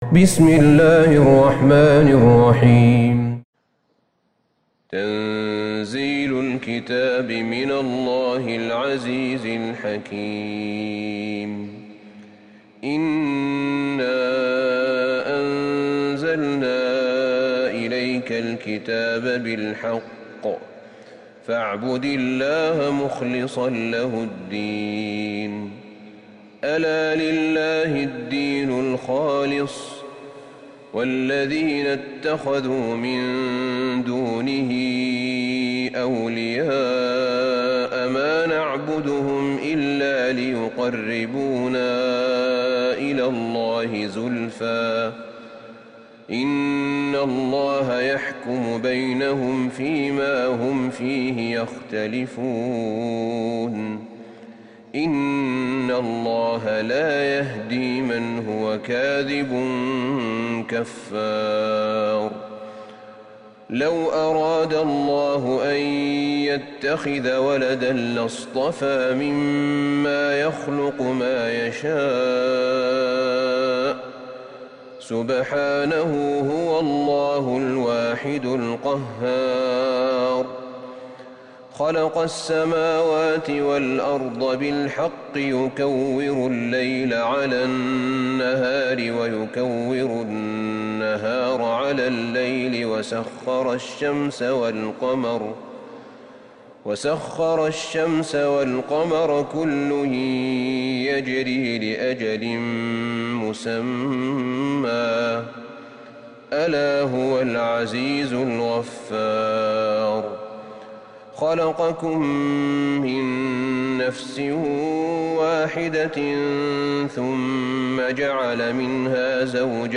سورة الزمر Surat AzZumar > مصحف الشيخ أحمد بن طالب بن حميد من الحرم النبوي > المصحف - تلاوات الحرمين